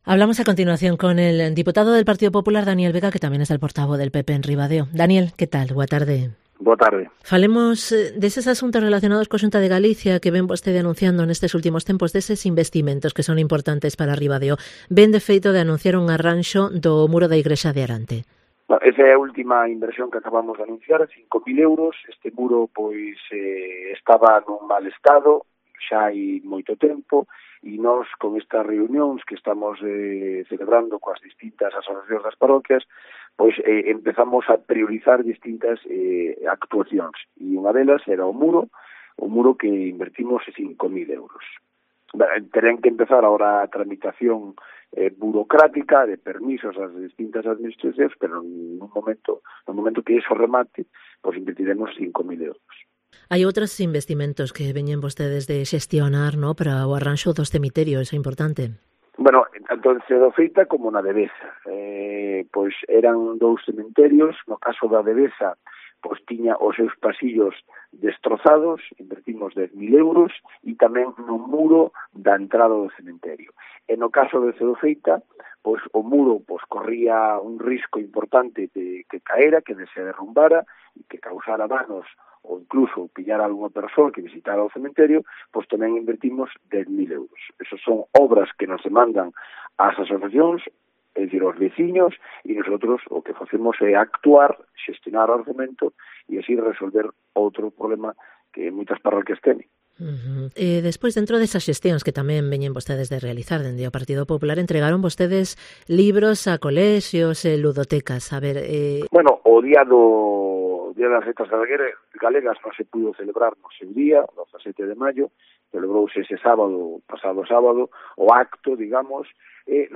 Entrevista con el diputado popular, DANIEL VEGA